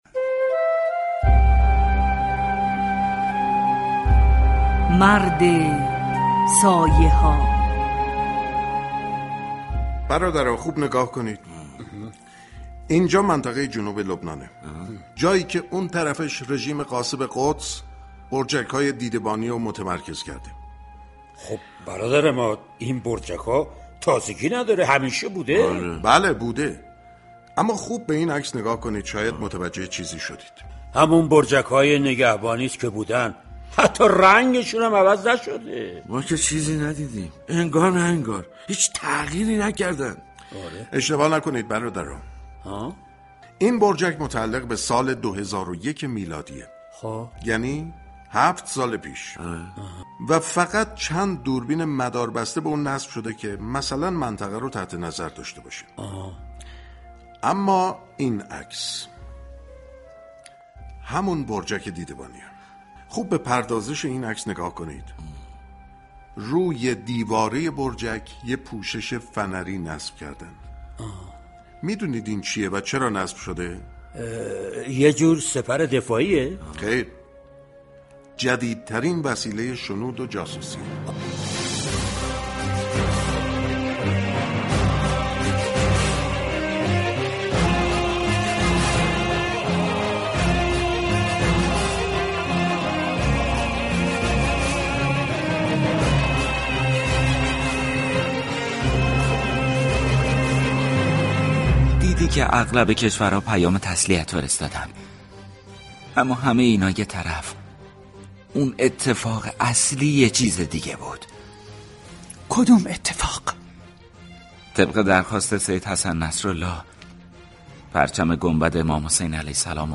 از روز دوشنبه بیست و نهم اردیبهشت ماه و در آستانه نزدیك شدن به روز قدس ، پخش سریال رادیویی «مرد سایه ها» در رادیو نمایش آغاز می شود.